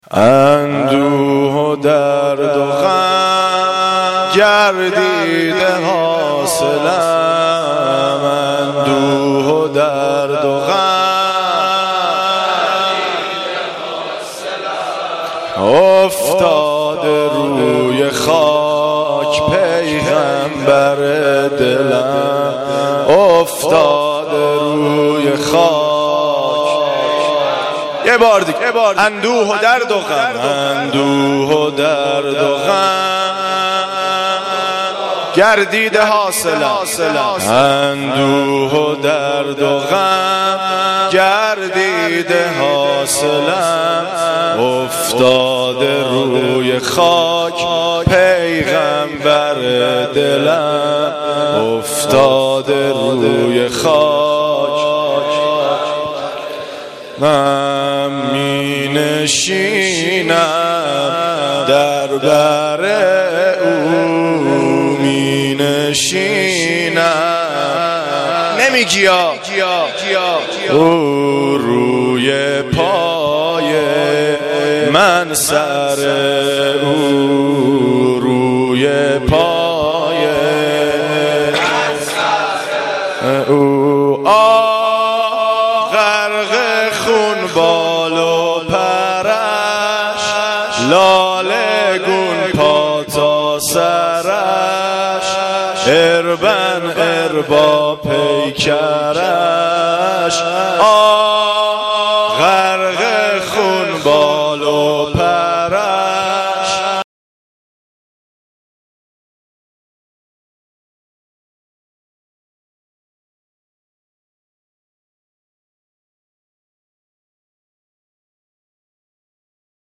نوحه
نوحه.mp3